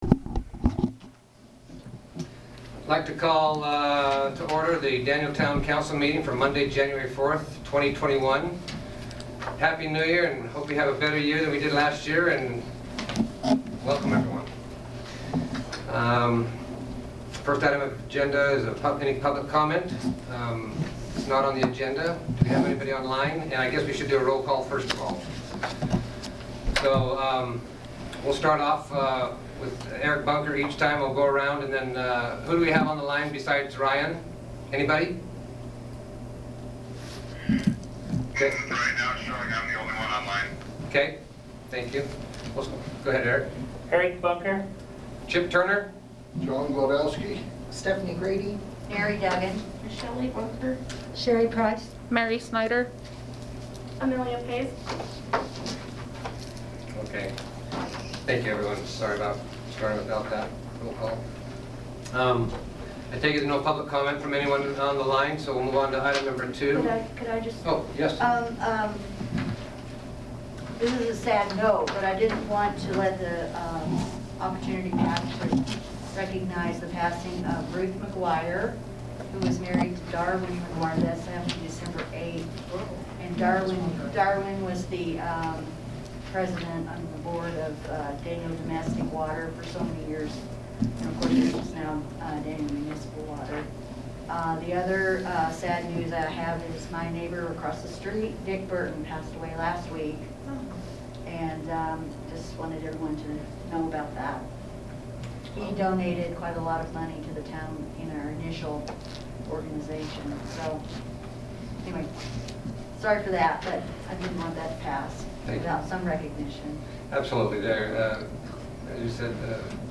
Town Council Audio minutes January 4, 2021